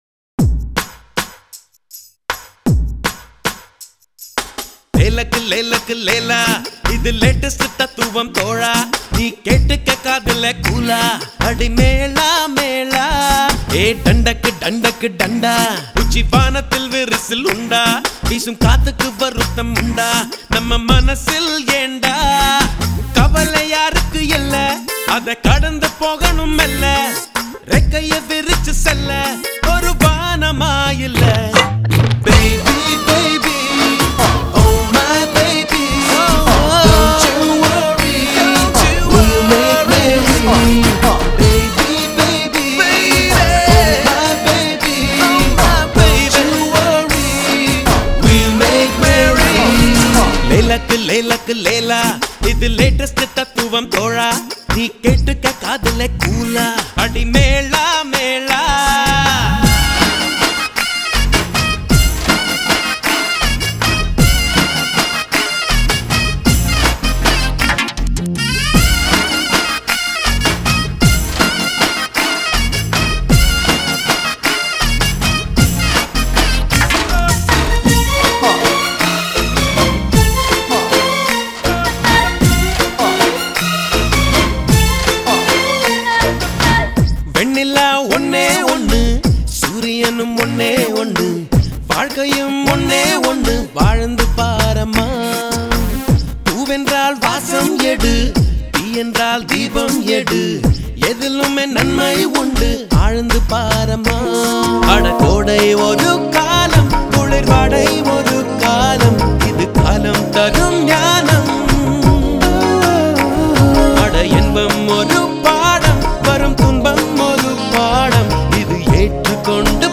Category: DJ AND BAND (BEND) ADIVASI REMIX